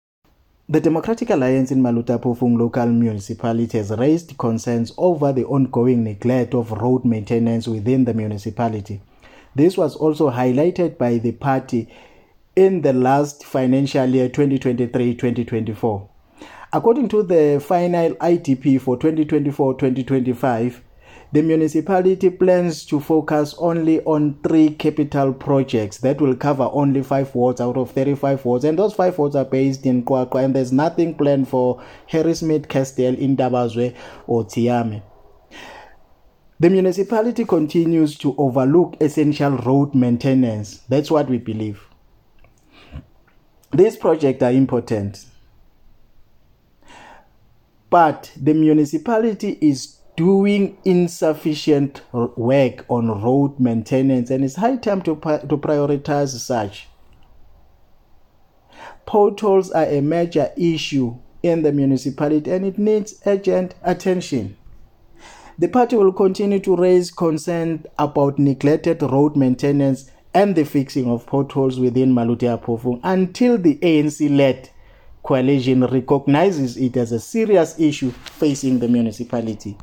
English soundbite by Cllr Richard Khumalo,